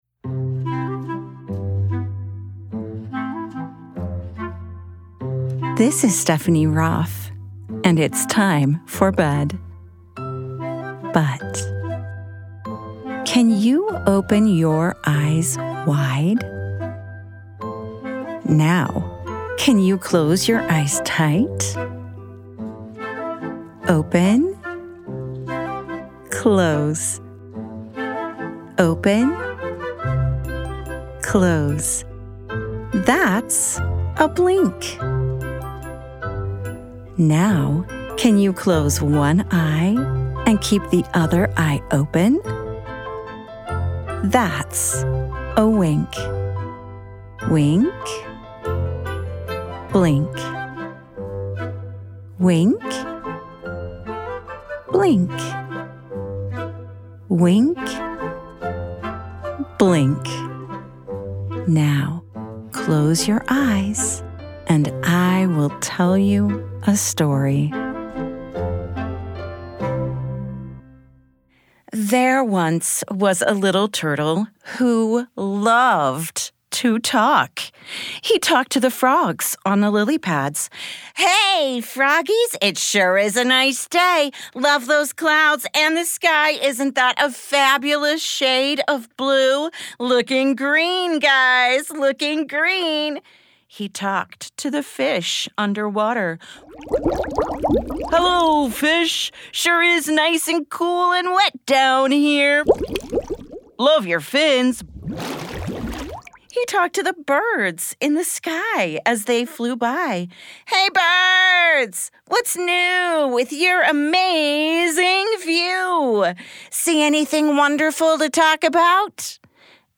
@ wink-bedtime-stories Wink is a production of BYUradio and is always ad and interruption free.